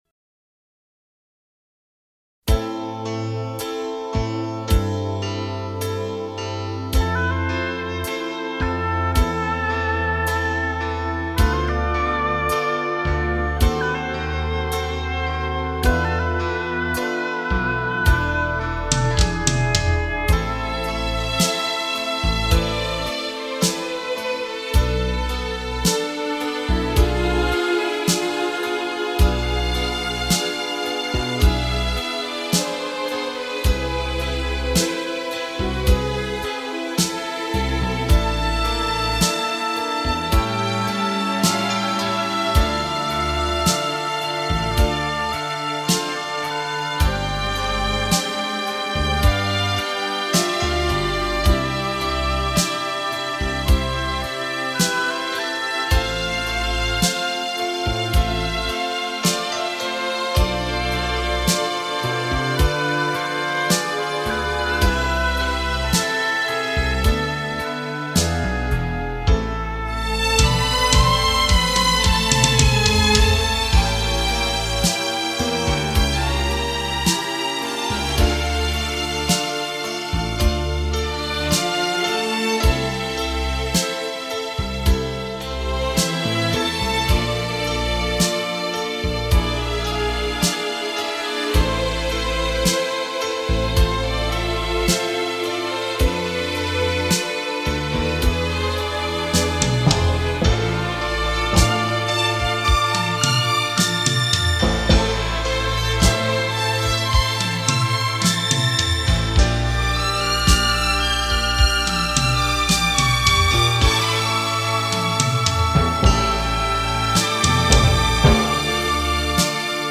Концертная, с конкурса "Україна має талант", , правда, она уже  была  и на старом, и на  этом сайте.